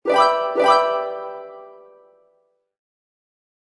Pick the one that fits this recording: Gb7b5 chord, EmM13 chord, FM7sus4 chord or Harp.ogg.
Harp.ogg